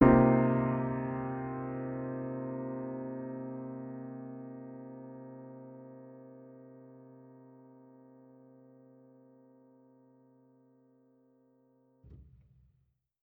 Index of /musicradar/jazz-keys-samples/Chord Hits/Acoustic Piano 2
JK_AcPiano2_Chord-Cm11.wav